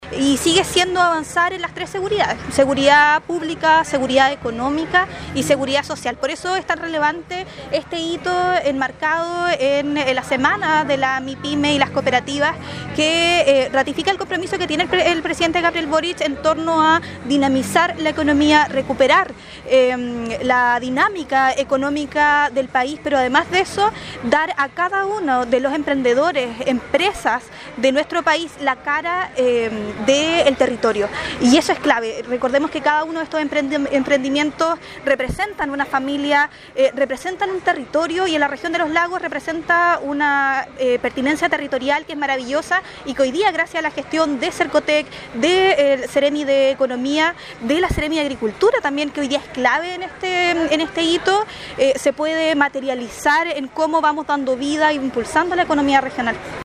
La Seremi de Gobierno, Danitza Ortiz, hizo notar la importancia de la realización de la Semana de la Pyme como una de las diversas iniciativas que se han llevado a través de la cartera enfocados a este sector productivo, e invitó a todos los interesados a informarse sobre las iniciativas a realizarse en dichas fechas.